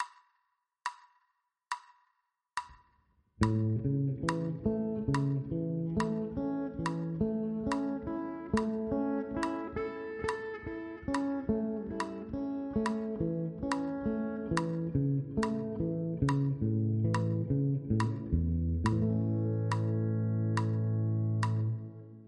Ex 2 – E-Dur Arpeggio